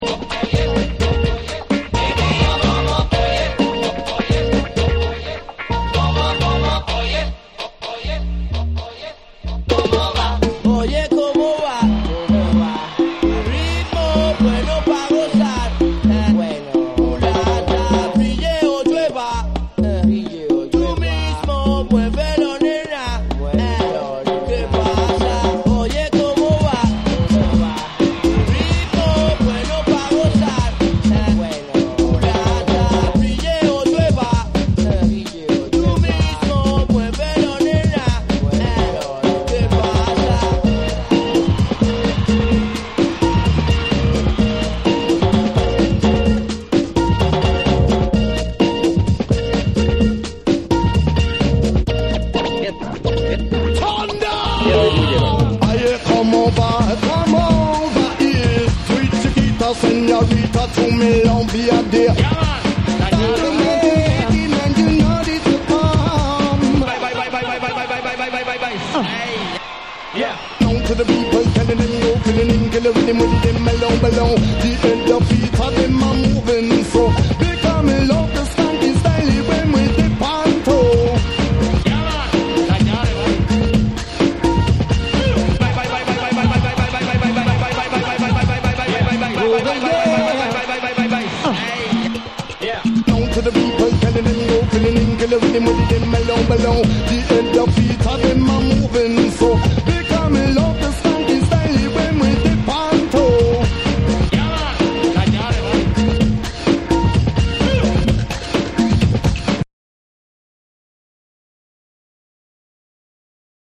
両面共にラテン・ネタ使いでガヤ（歓声）がアクセントとなったパーティー・ブレイクを収録。
BREAKBEATS / ORGANIC GROOVE